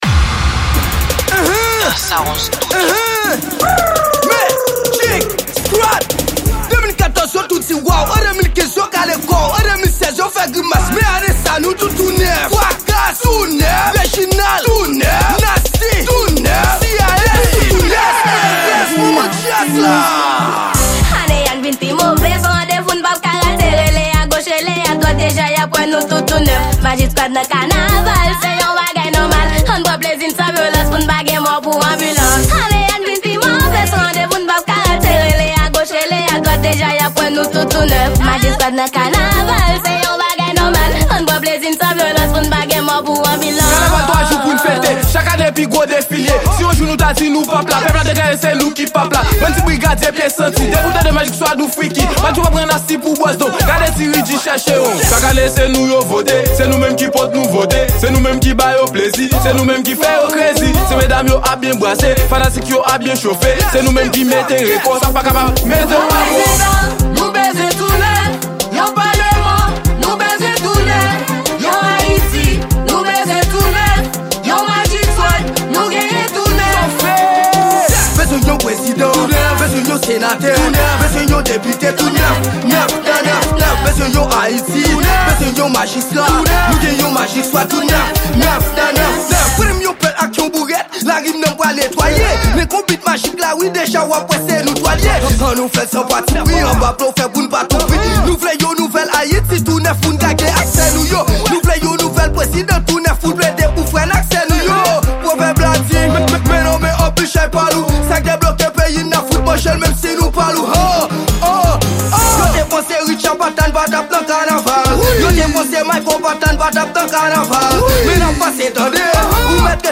Genre: K-naval.